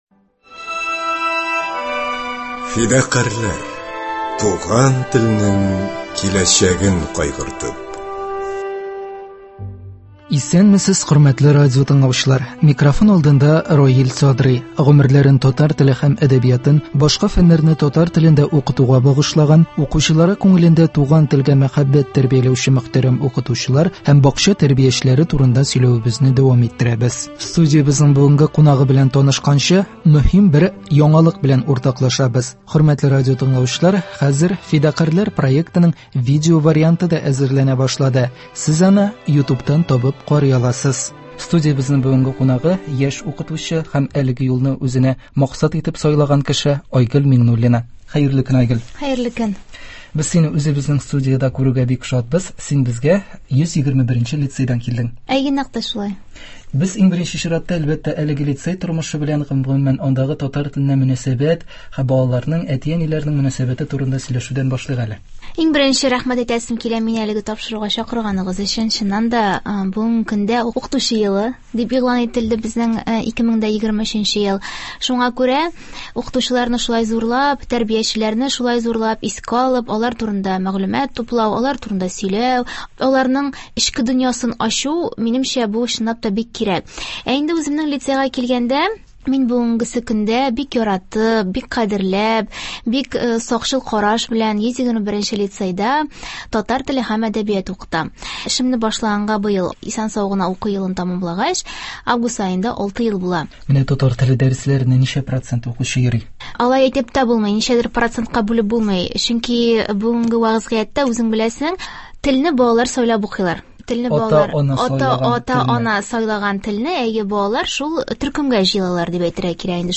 Студиябезнең бүгенге кунагы – яшь укытучы